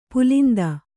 ♪ pulinda